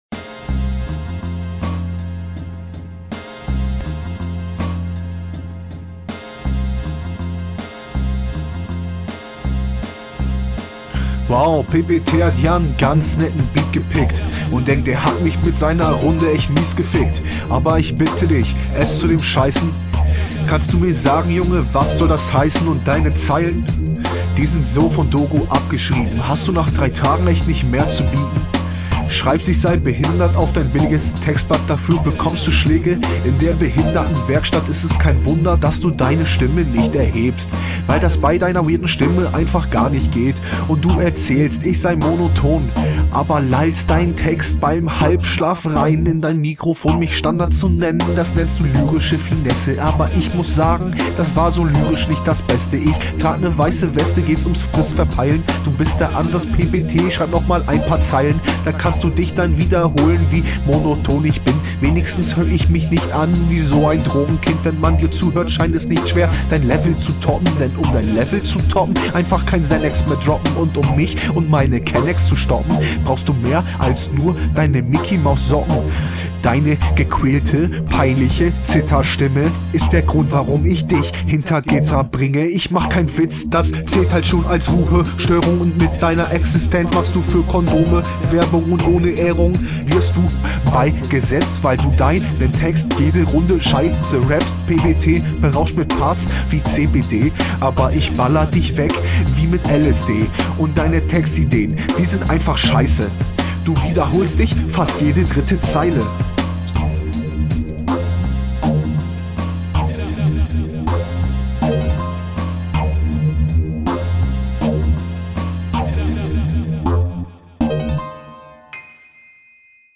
diese Triolen passagen klingen sehr unrund aber deine Quali war schlimmer....